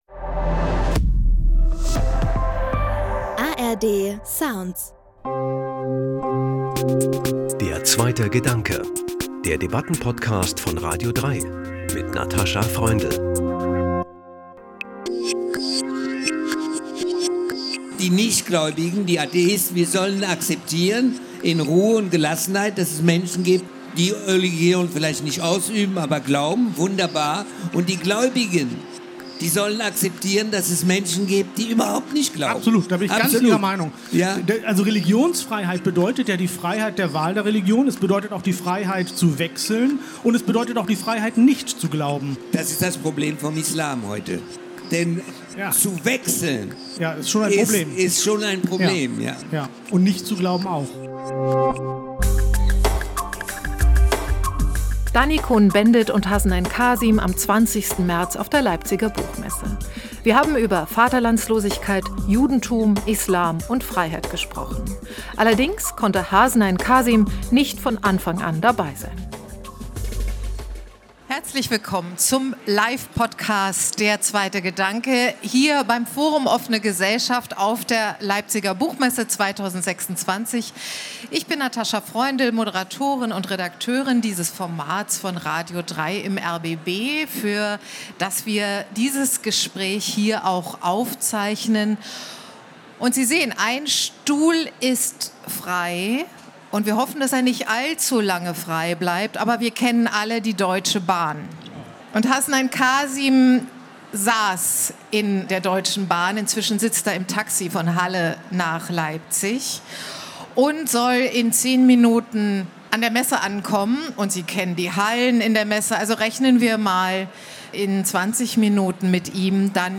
Dany Cohn-Bendit und Hasnain Kazim Aufgezeichnet am 20.03.2026 beim Forum Offene Gesellschaft auf der Leipziger Buchmesse Treffen sich ein Jude und ein Muslim in Leipzig.